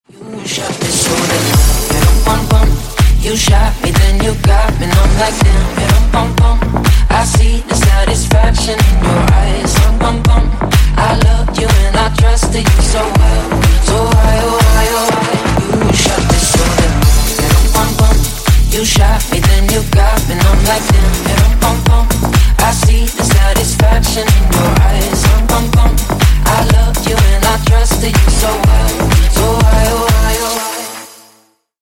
Клубные Рингтоны
Танцевальные Рингтоны